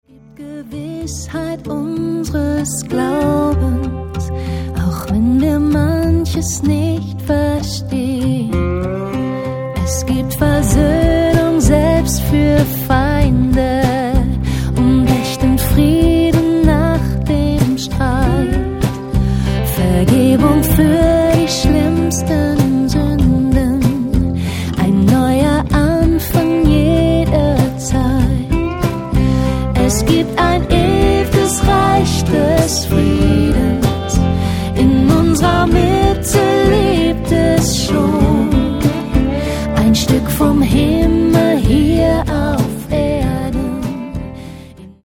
• Stil/Genre: Gemeindelied
• Sachgebiet: deutscher Lobpreis